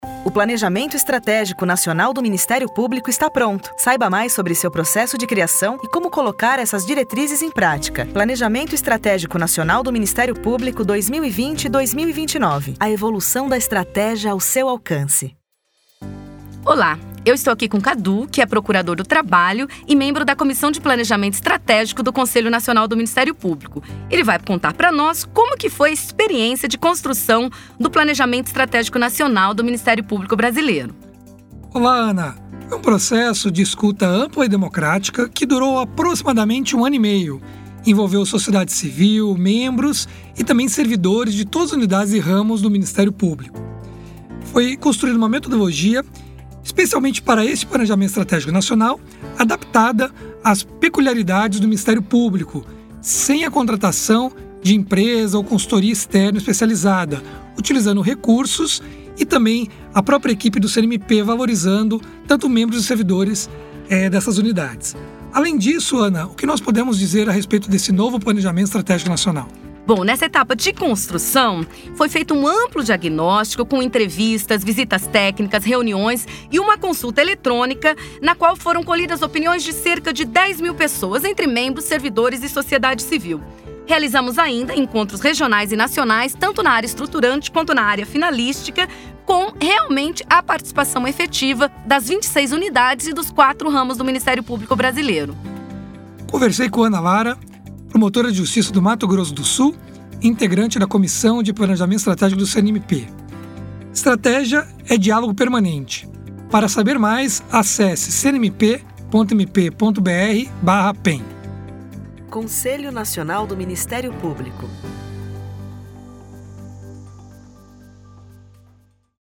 A iniciativa conta com cards para as redes sociais, gif, cartaz, e-mail marketing e podcasts com entrevistas com membros que participaram do projeto.